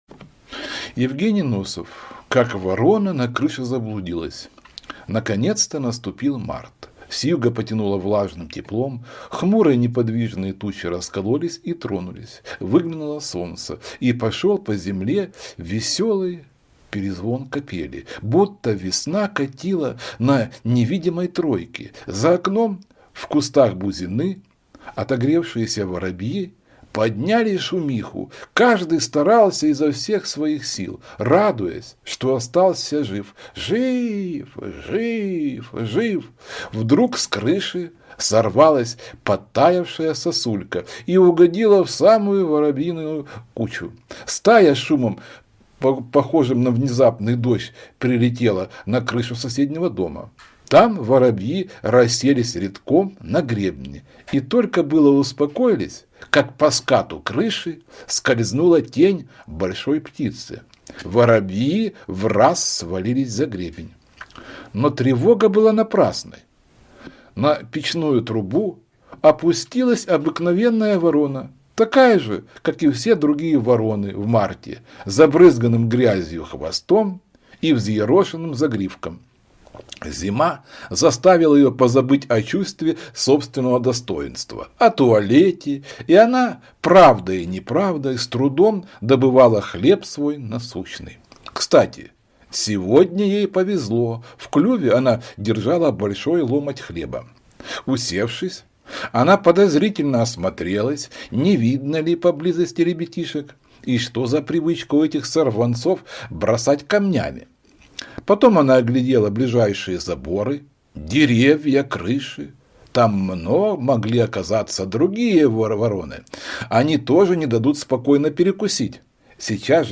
Аудиосказка «Как ворона на крыше заблудилась»
Чтение ужасное, постоянные запинки, некоторые слова прочитаны неправильно